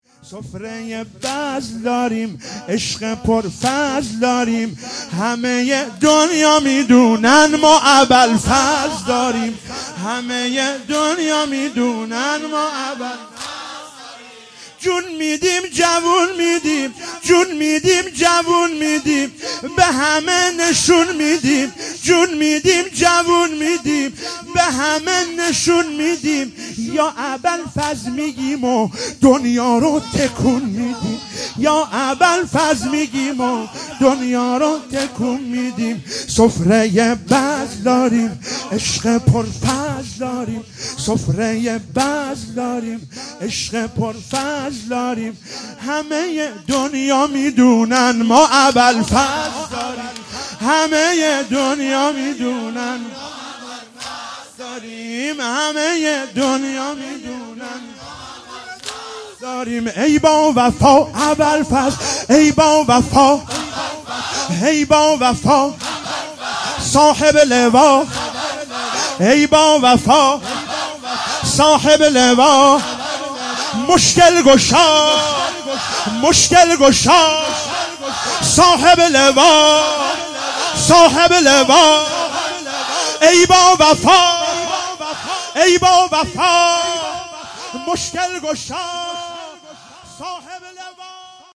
شب ششم محرم 97 - واحد - سفره ی بزل داریم